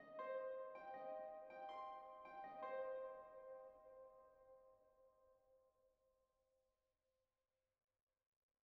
rythmic_maia_arpeggio.wav